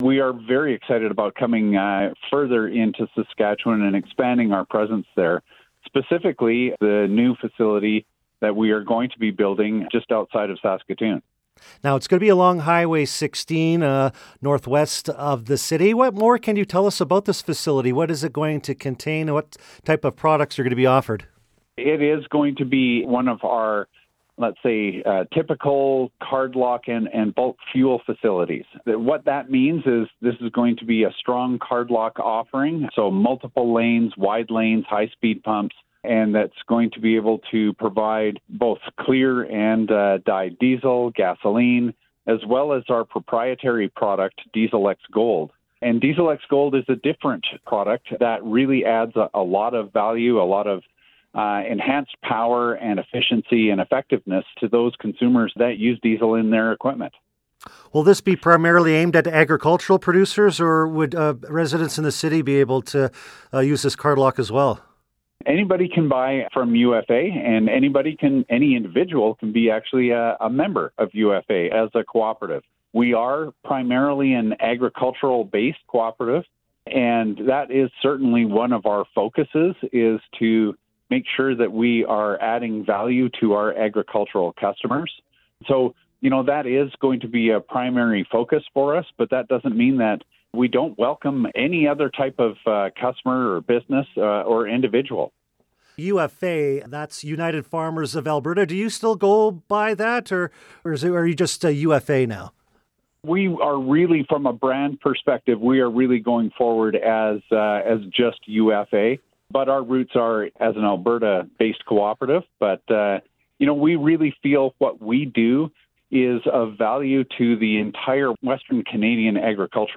full-ufa-interview-for-online.mp3